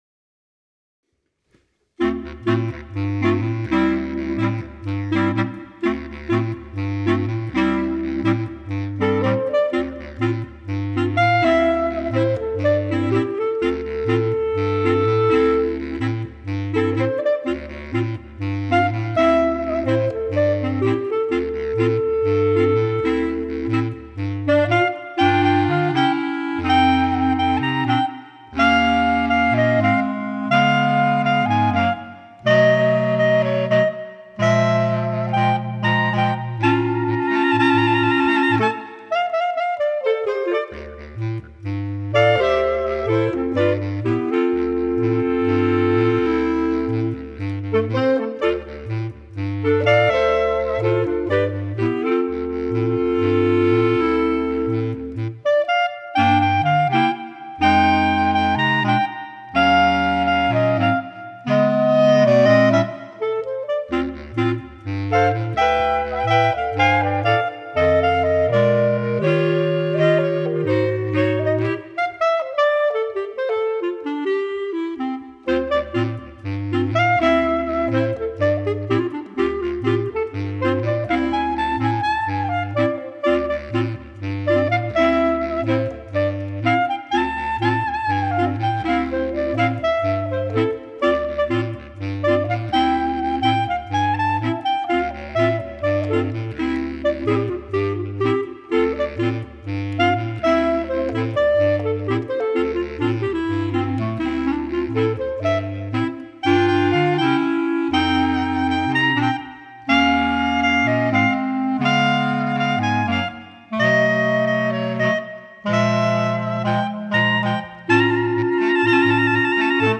Detective Music